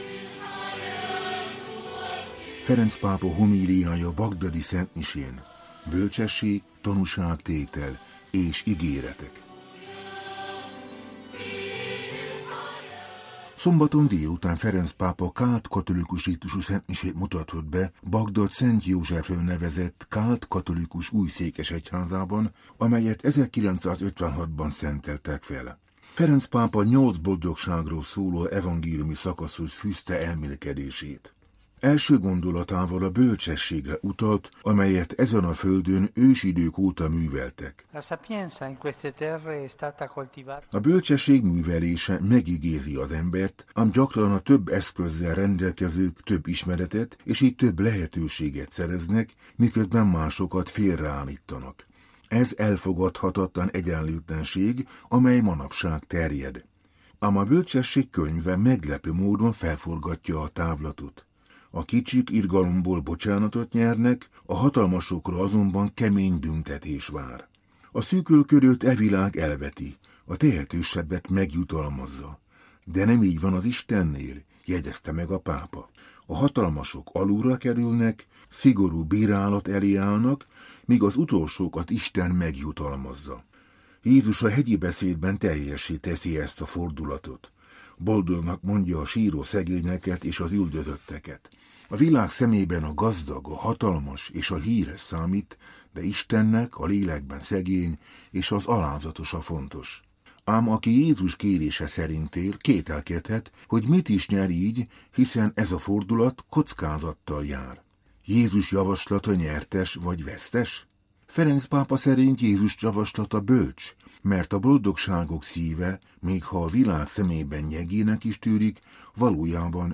[Grem] Ferenc p�pa mai hom�li�ja (8' 34")